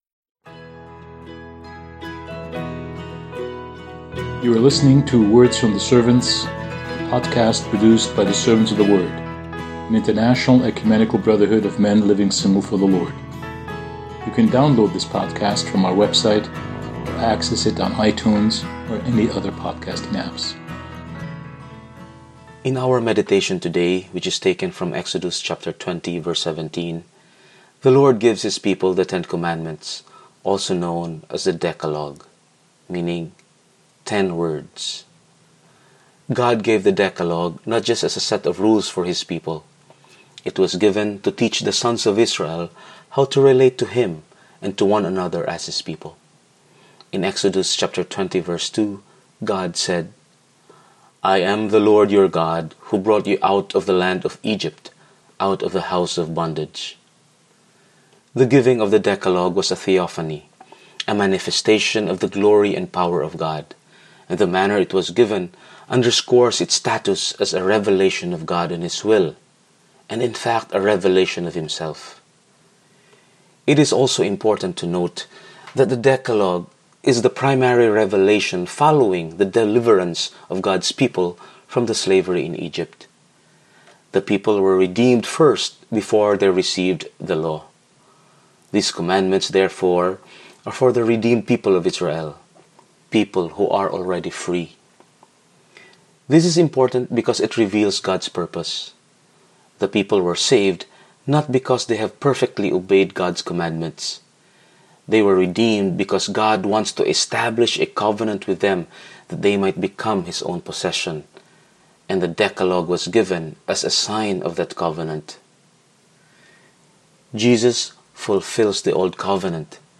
Throughout the 40 Days of Lent, our Words from the Servants podcast will feature brothers from around the world as they give daily commentary on the books of Exodus and Hebrews.